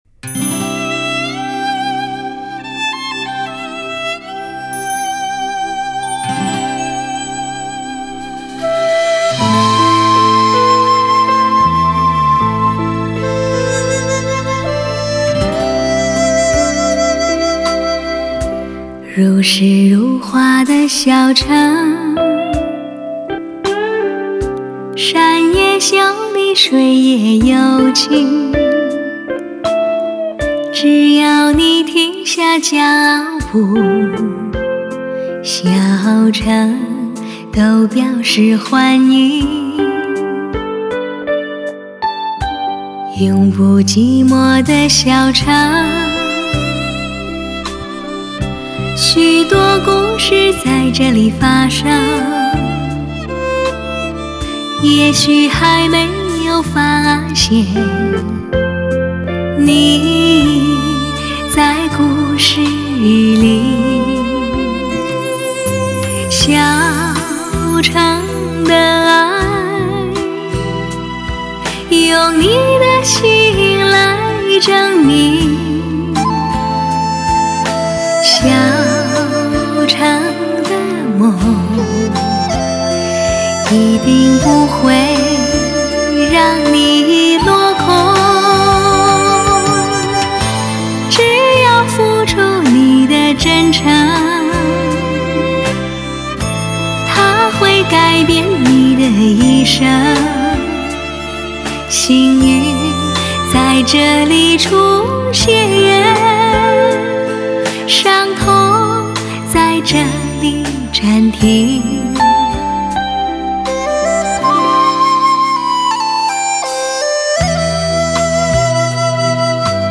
日本直刻黑胶CD
恬静柔美 靓绝女声 极究录音 尊爵享受